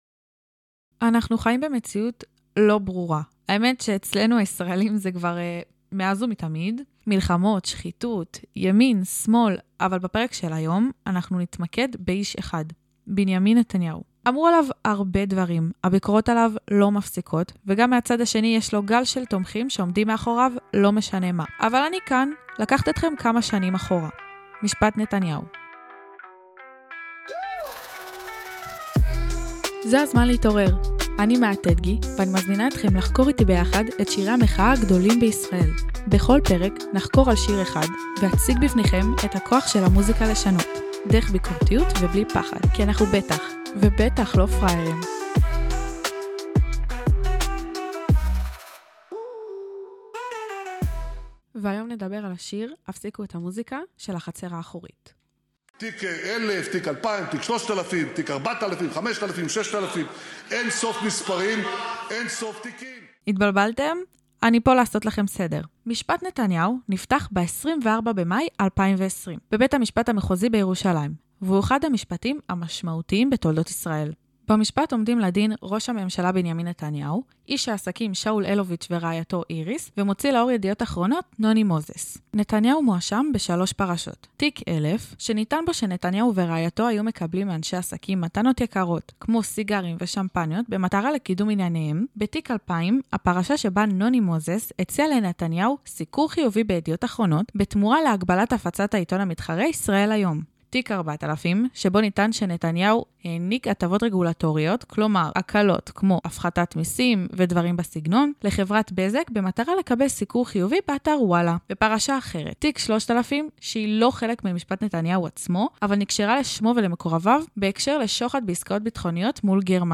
שיר שנכתב בתקופת "מחאת בלפור" (2020) ואף נחשב להמנון שלה. בפרק ריאיון עם תומר יוסף, זמר, יוצר וחבר ההרכב "החצר האחורית" שיספר על אחורי הקלעים של תהליך יצירת השיר.